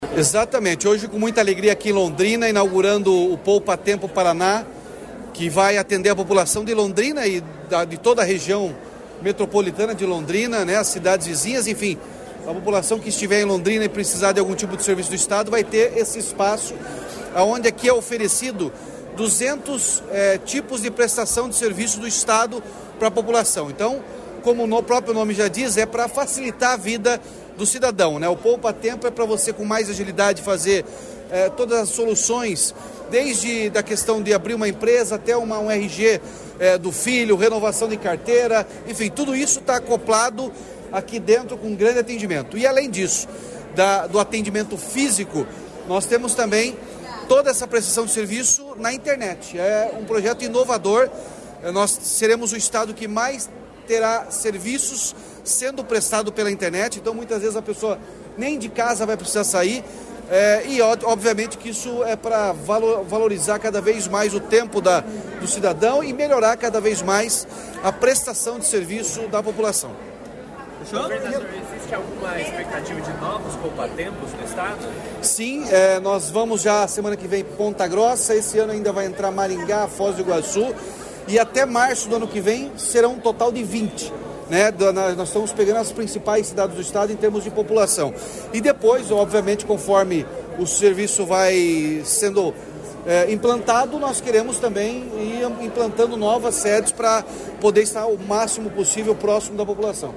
Sonora do governador Ratinho Junior sobre a inauguração do Poupatempo de Londrina